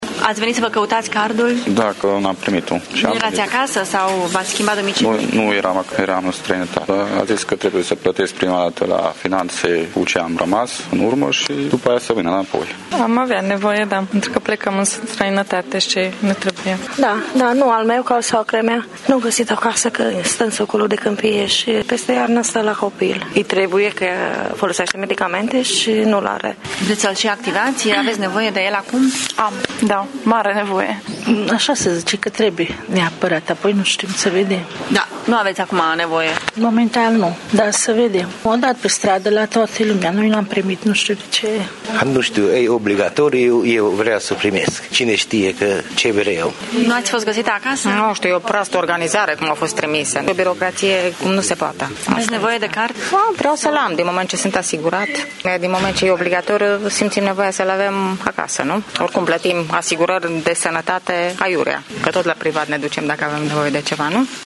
Unii au nevoie de ele pentru a merge la medic, alții spun că doar vor să le aibă la îndemână, pentru orice eventualitate: